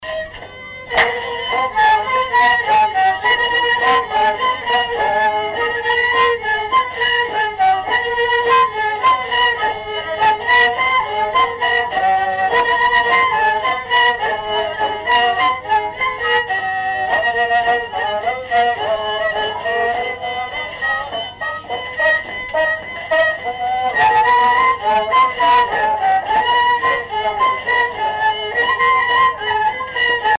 Genre laisse
Répertoire d'un bal folk par de jeunes musiciens locaux
Pièce musicale inédite